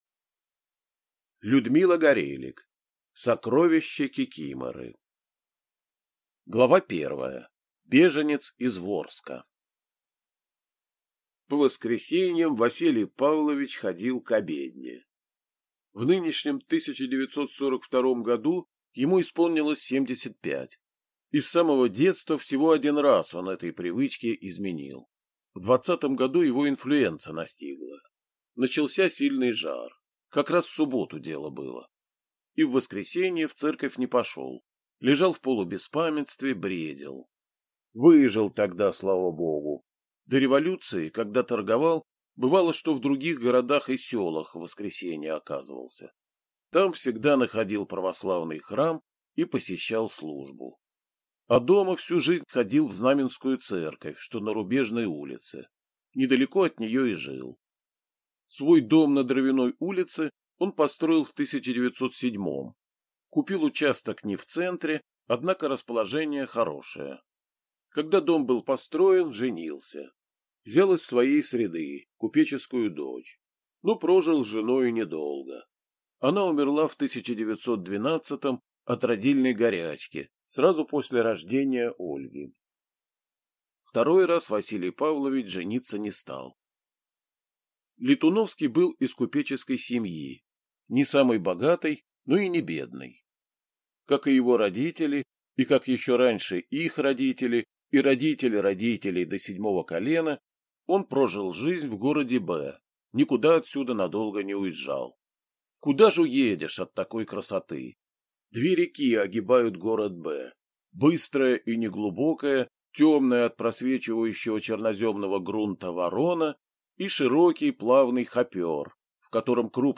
Аудиокнига Сокровище кикиморы | Библиотека аудиокниг